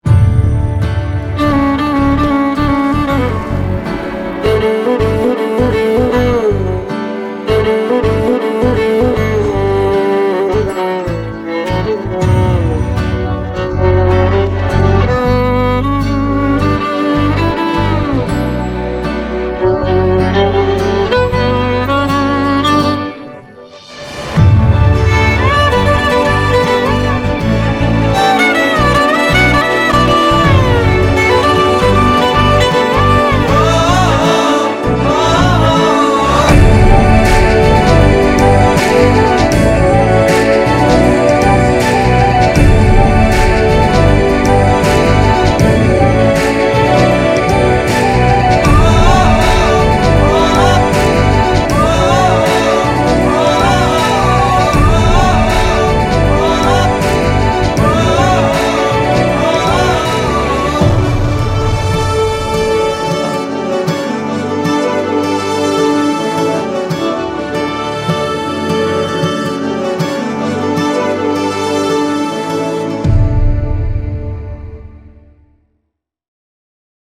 with reduced disturbances.
We extended it like an official one with good sound balance.
• Mixing & Vocals Removal